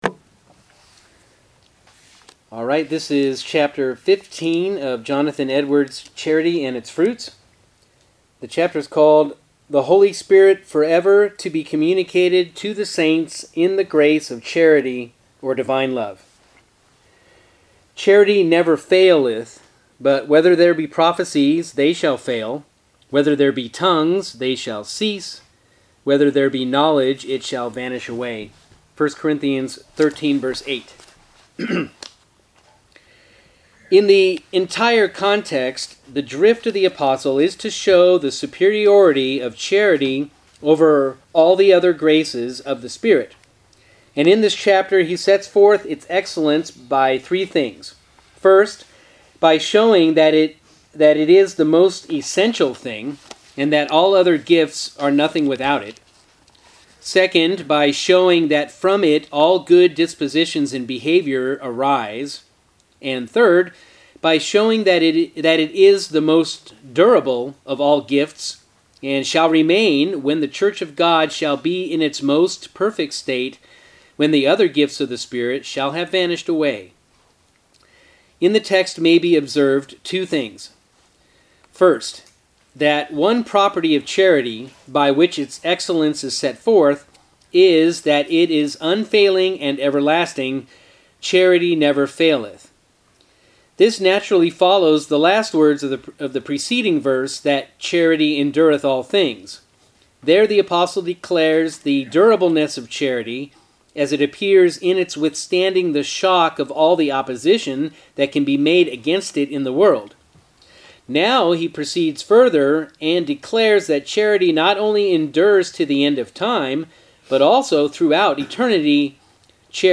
After chapter one, someone suggested we record them; and I figured, why not, since it was being read anyway.